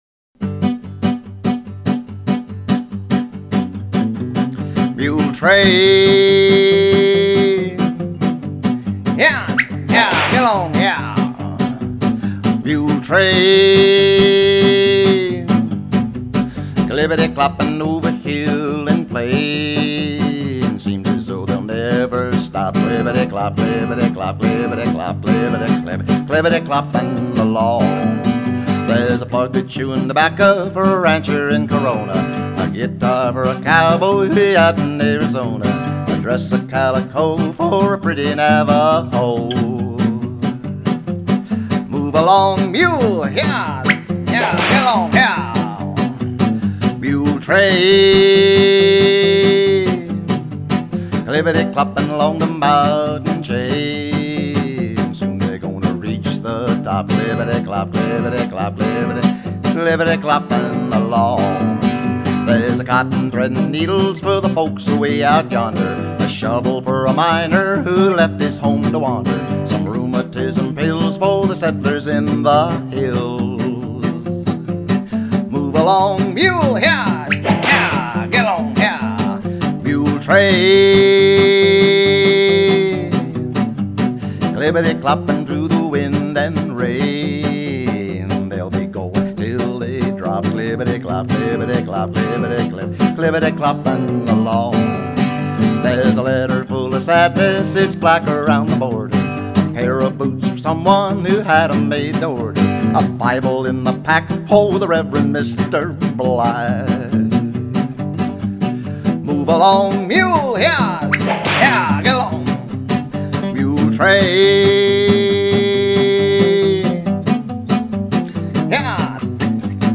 cowboy songs